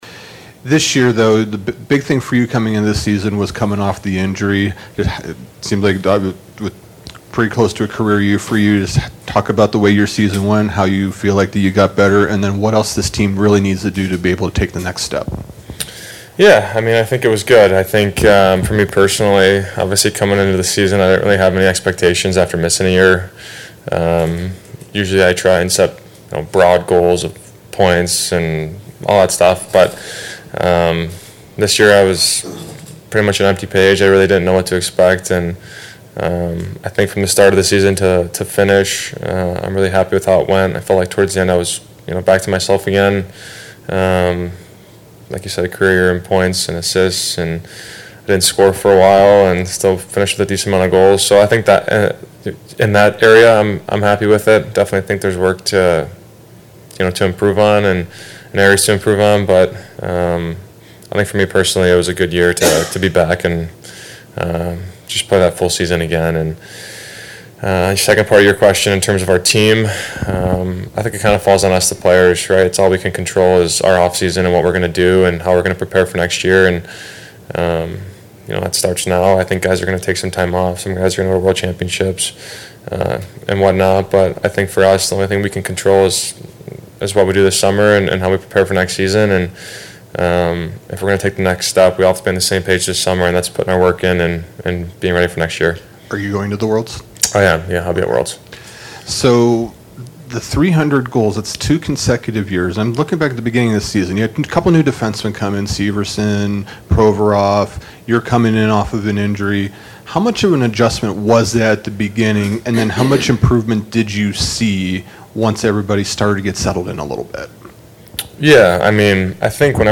Zach Werenski CBJ Defenseman 2023-24 Season Ending Exit Interview April 2024.mp3